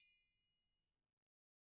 Triangle6-Hit_v1_rr1_Sum.wav